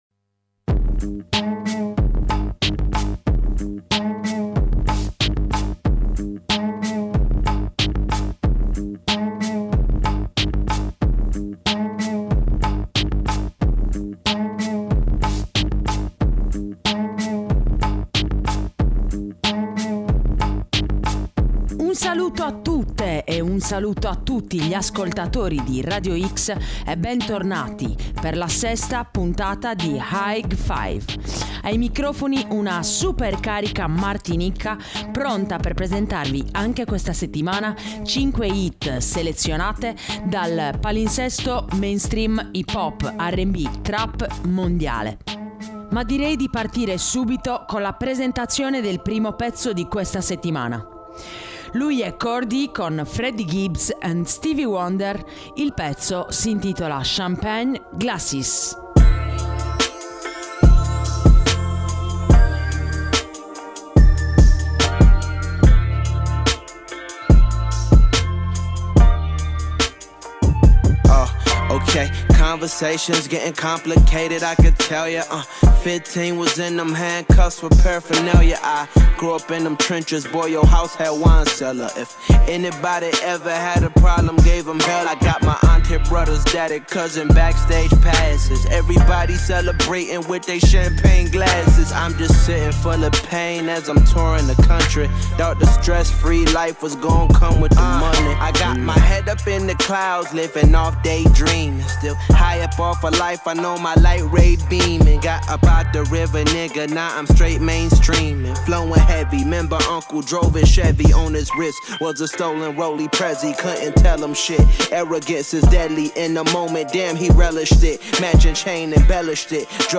hip hop / rap / trap